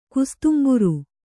♪ kustumbaru